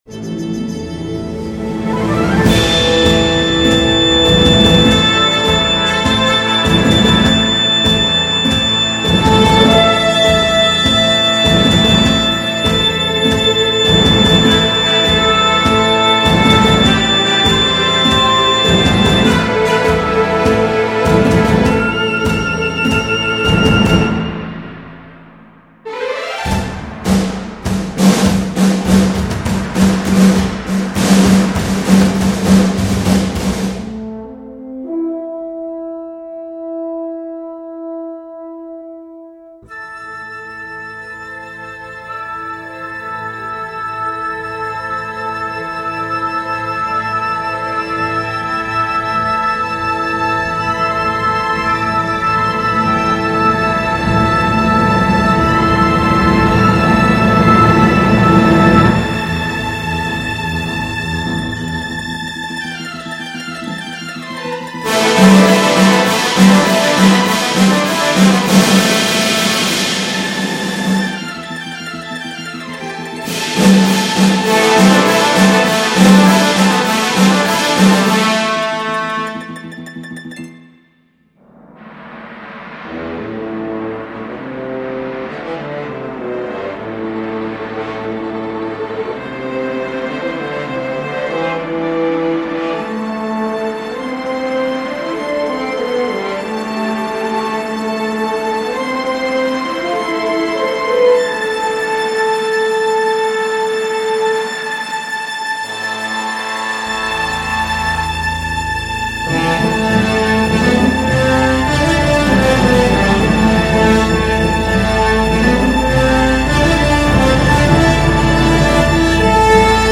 Symphony no 1 in A minor (I) March - Orchestral and Large Ensemble